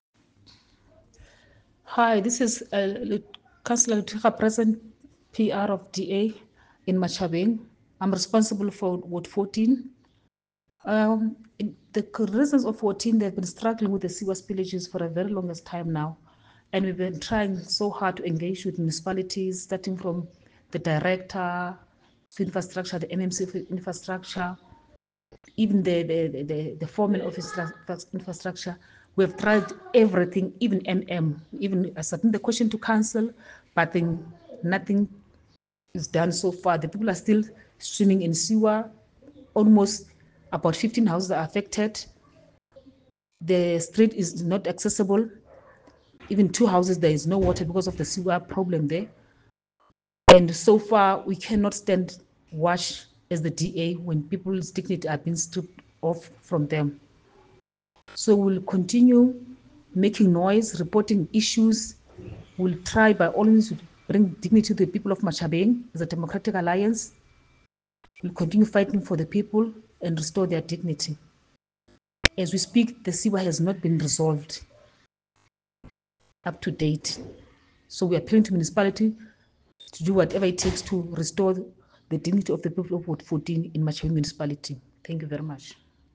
English soundbite by Cllr Luttiga Presente, Afrikaans soundbite by Cllr Jessica Nel and
incomplete-sewer-project-in-Ward-14-ENG.mp3